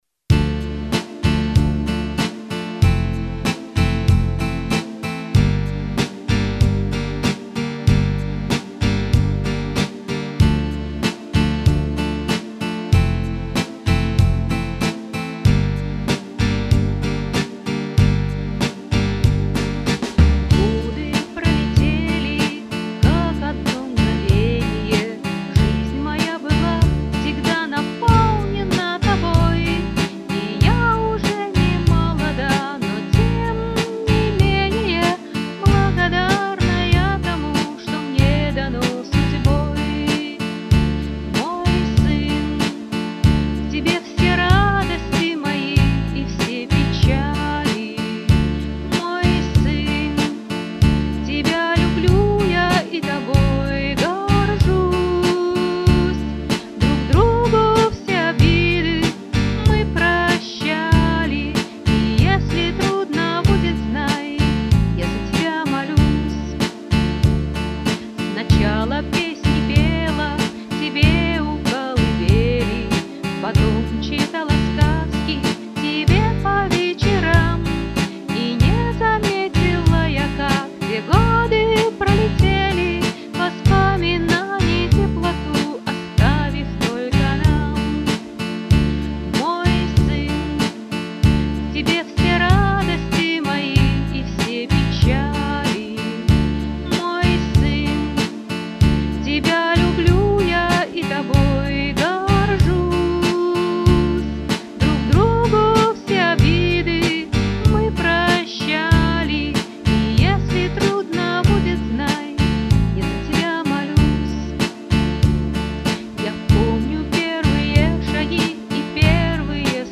Авторские песни